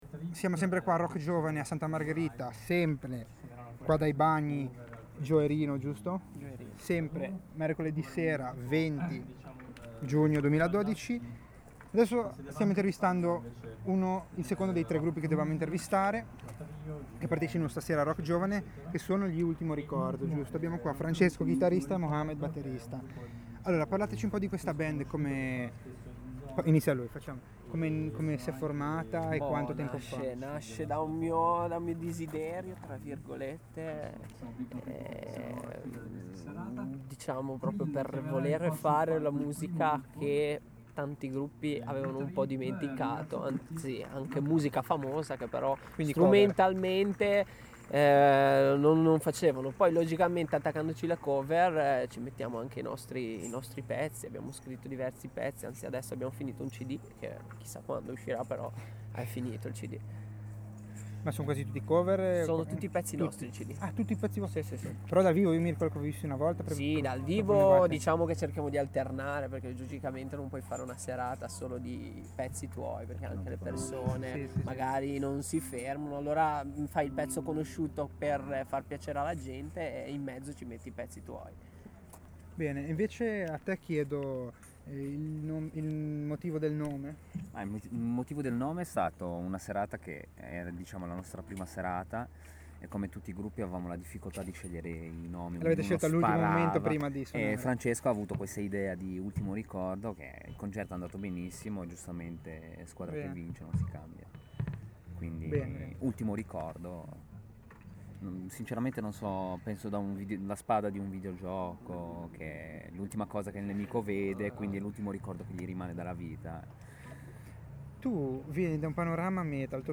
Intervista a Ultimo Ricordo
In occasione della seconda serata dell'iniziativa RockGiovane abbiamo intervistato gli Ultimo Ricordo